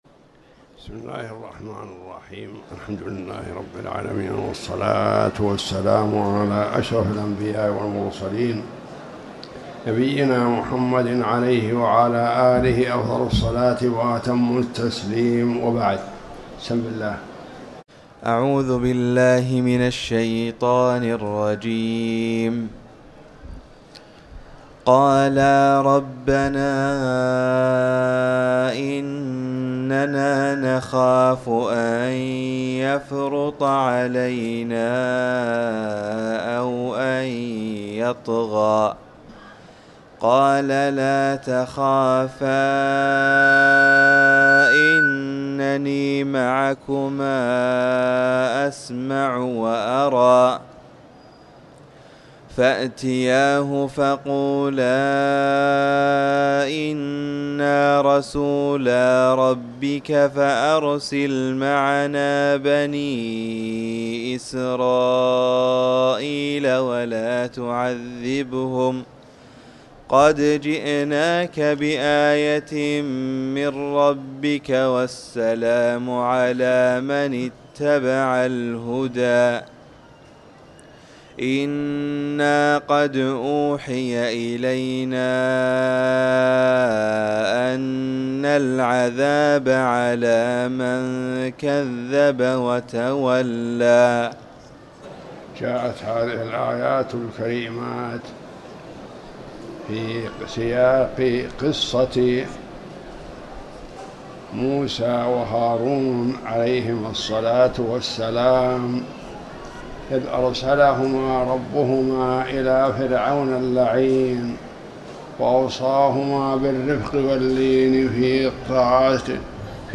تاريخ النشر ١٥ صفر ١٤٤٠ هـ المكان: المسجد الحرام الشيخ